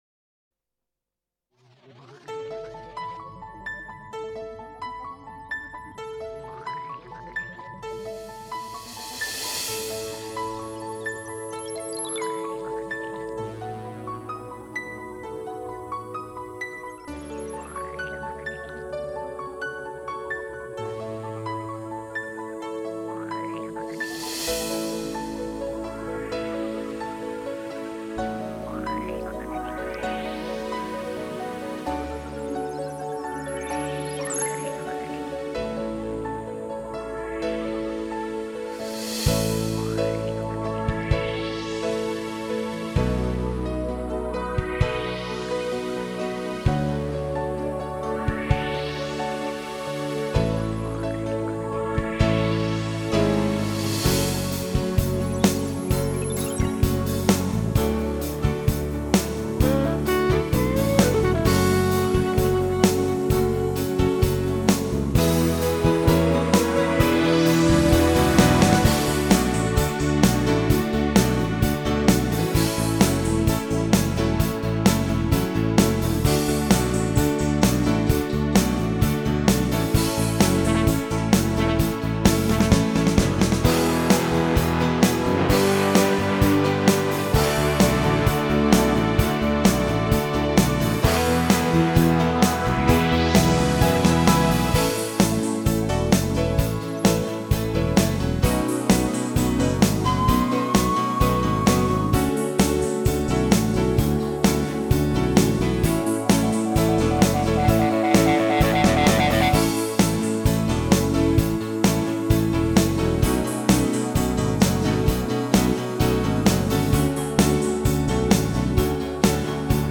Genre: Pop.